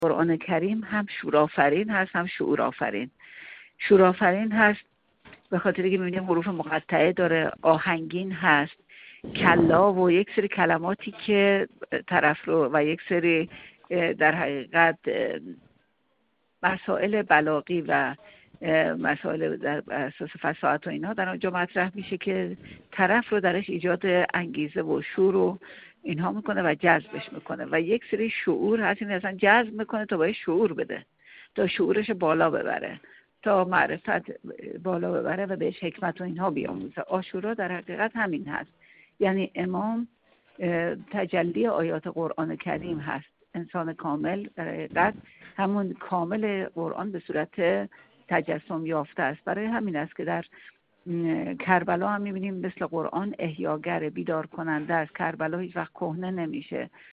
لاله افتخاری در گفت‌وگو با ایکنا: